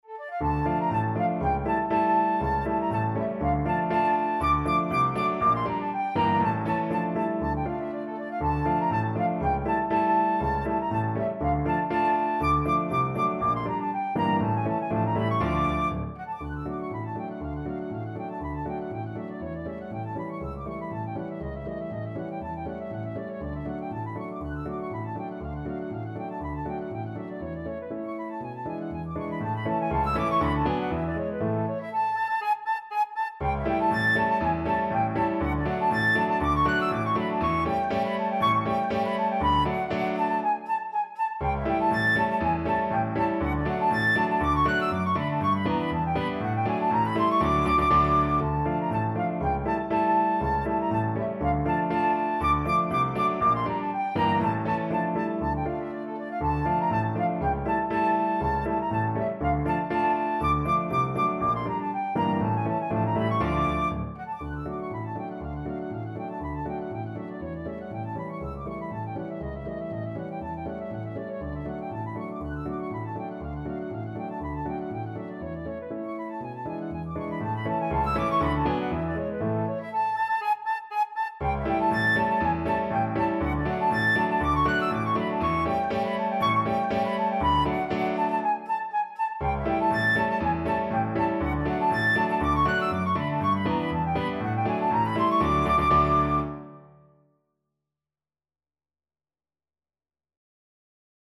2/4 (View more 2/4 Music)
Classical (View more Classical Flute Music)